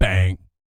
BS BANG 04-R.wav